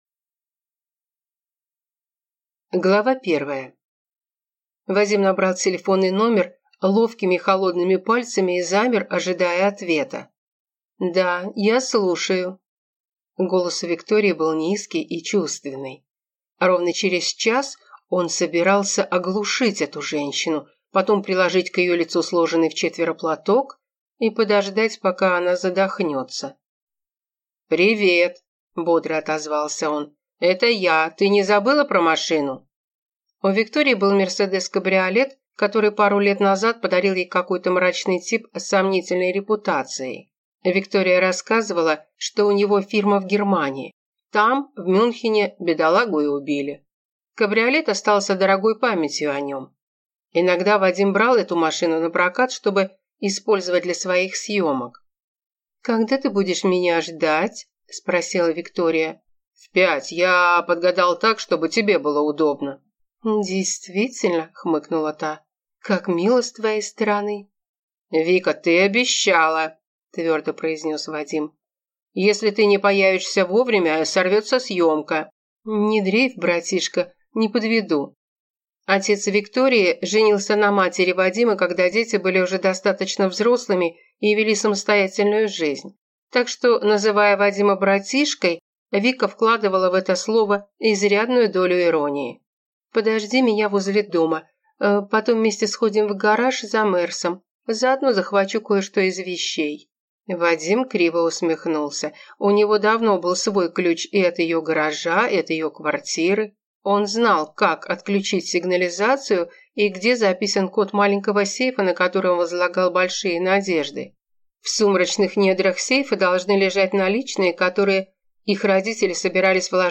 Аудиокнига Умри красивой, или Салон медвежьих услуг | Библиотека аудиокниг